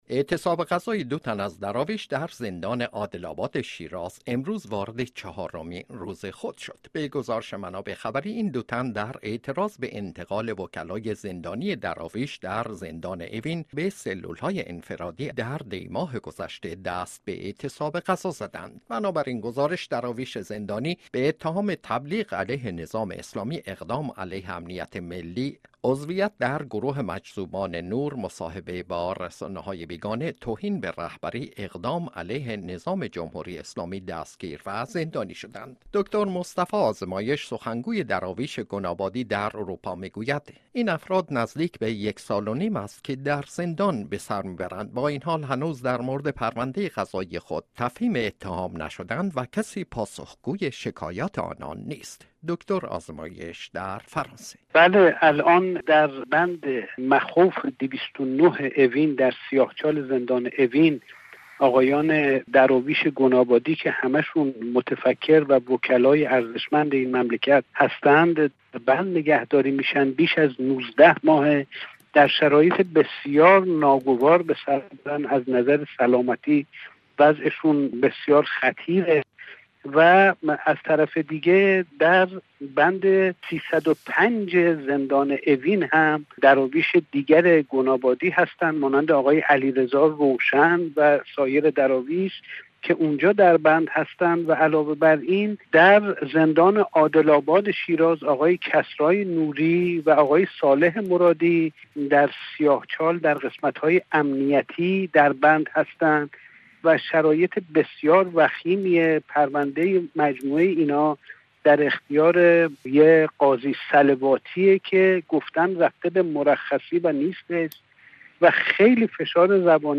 گفتگوی رادیو فرانسه